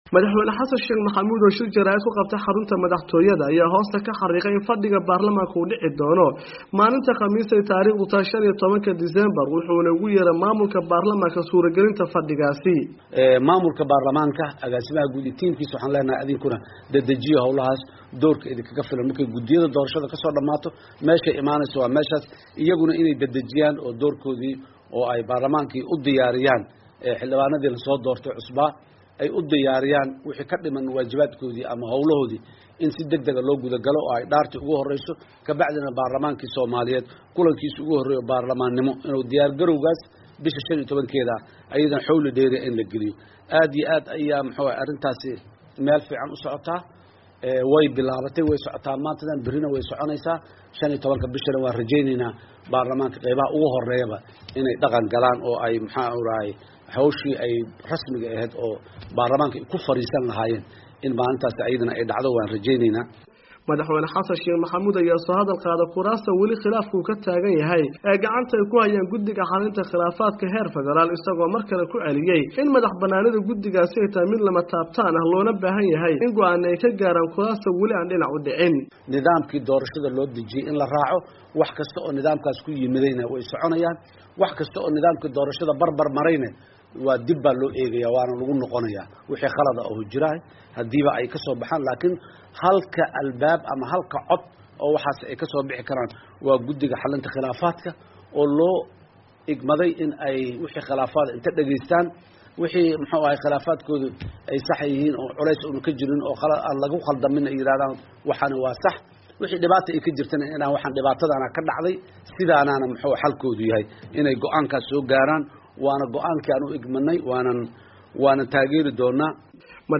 Madaxweynaha oo shir jaraa'id qabtay